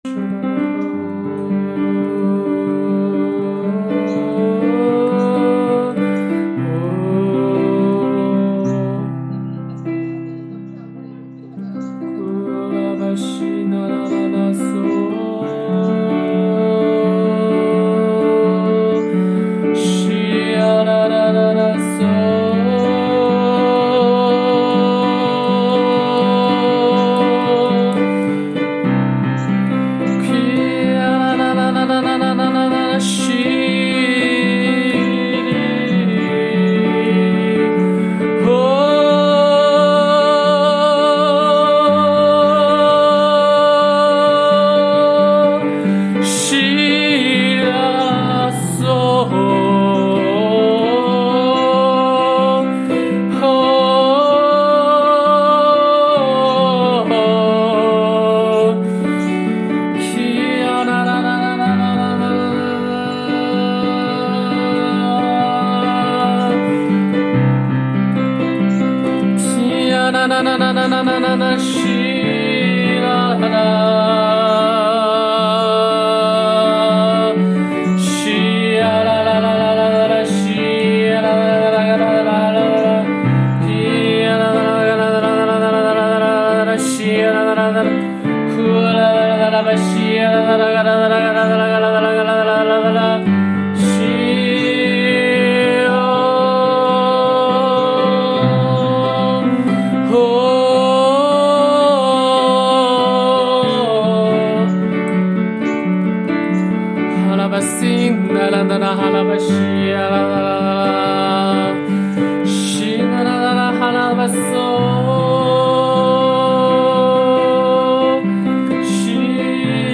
HAKA祷告敬拜MP3 启示性祷告： 持续祷告：祈求神的旨意成就在我们的身上，带领做新事！